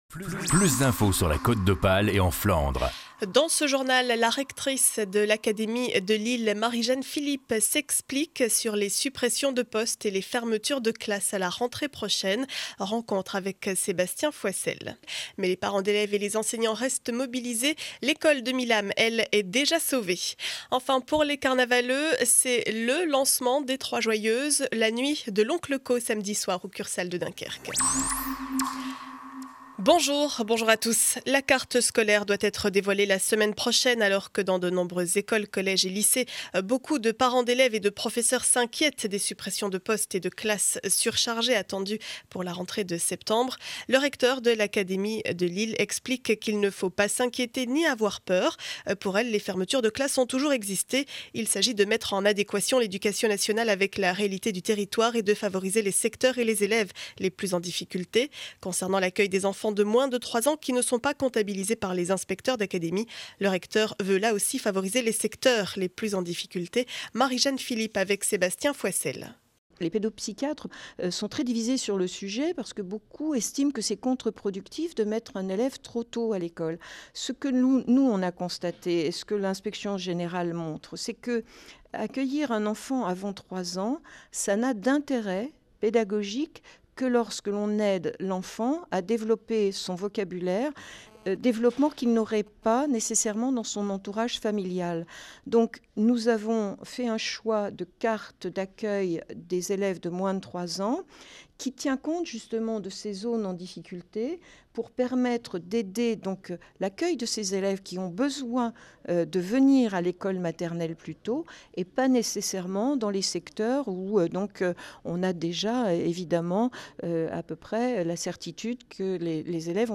Journal du vendredi 17 février 2012 7 heures 30 édition du Dunkerquois.